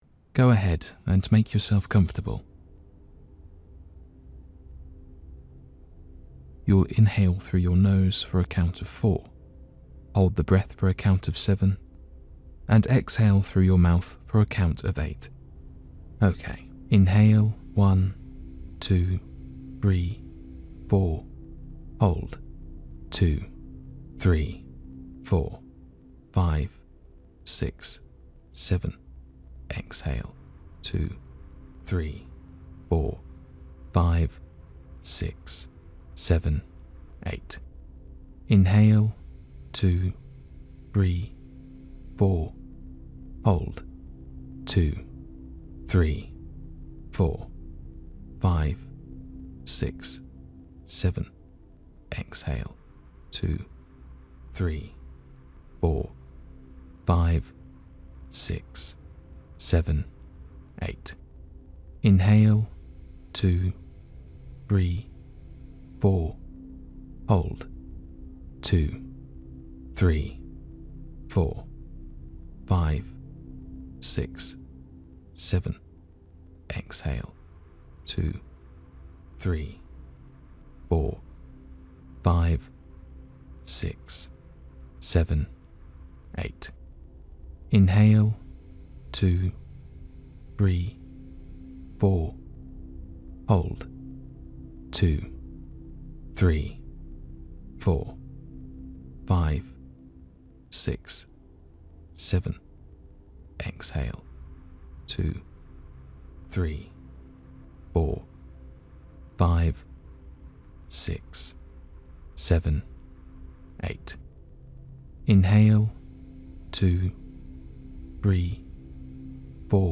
Breathing Exercise